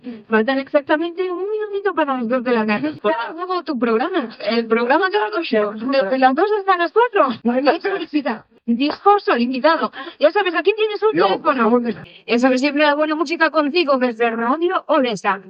Presentació del programa dedicat als discs dedicats